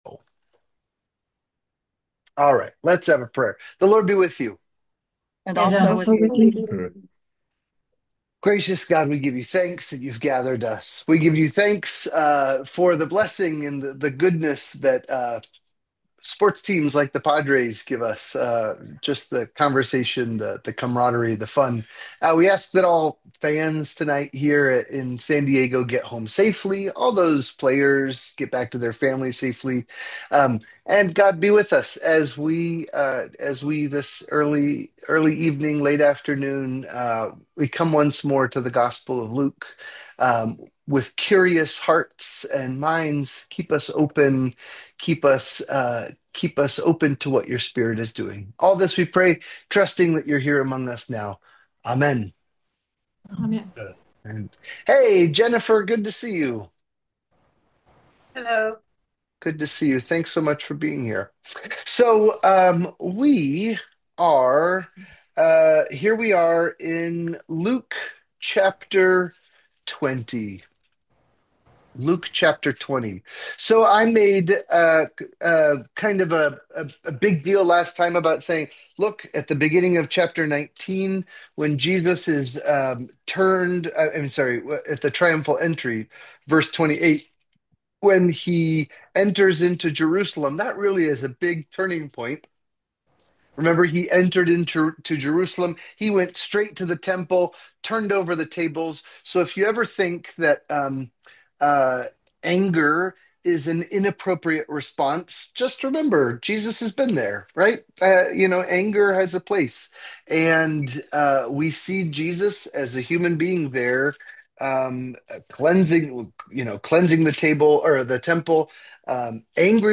Goats Bible Study - Gospel of Luke